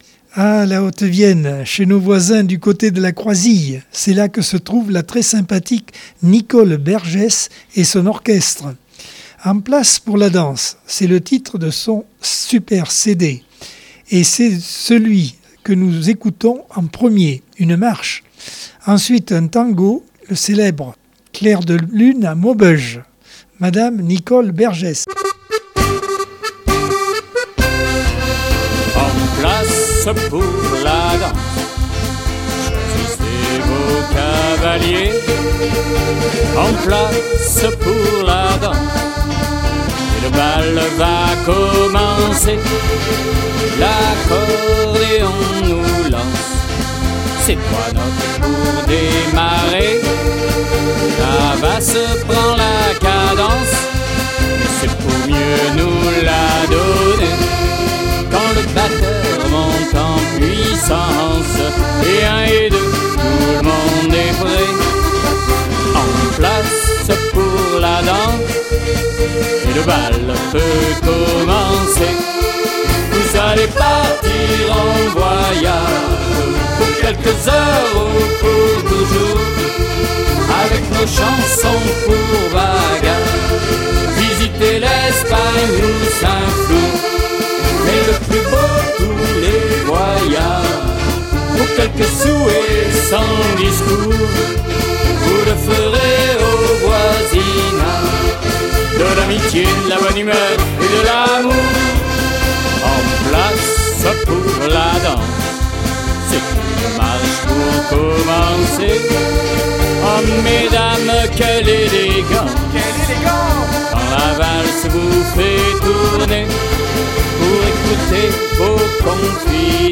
Accordeon 2022 sem 17 bloc 4 - Radio ACX